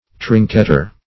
Trinketer \Trin"ket*er\, n.